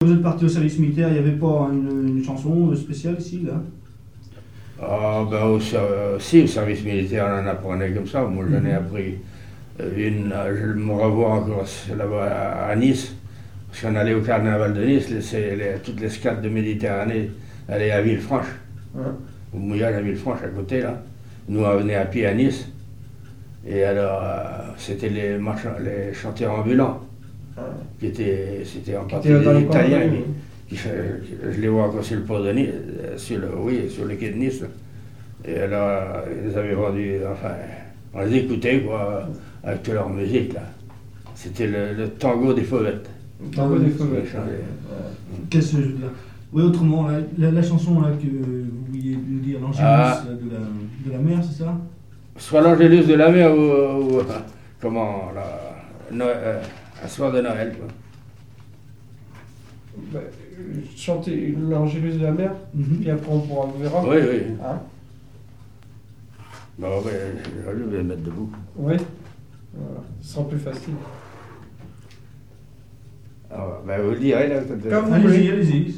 chanteur(s), chant, chanson, chansonnette
chansons maritimes et vie professionnelle d'un marin